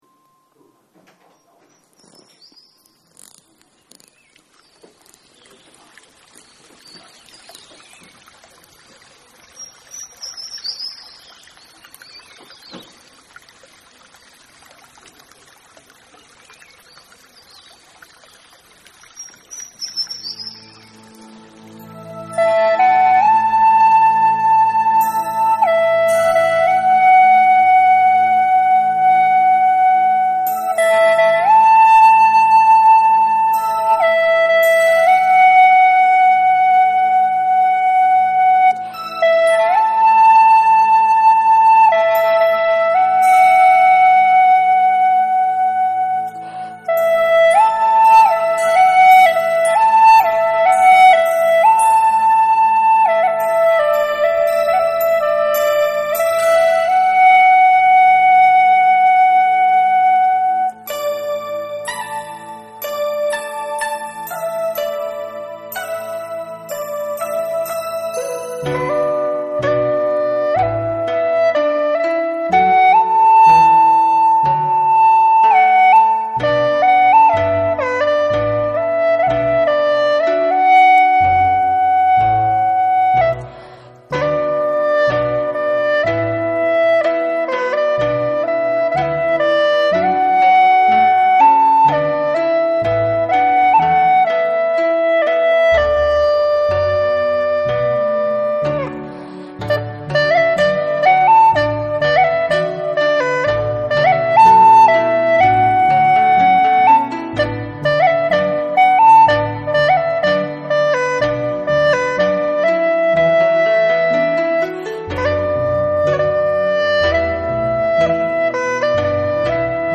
发的是手机录音链接，请朋友们老师们多给批批，谢谢了!
快板的前面部分单吐吹得很好，但有的地方连音效果没有吹出来。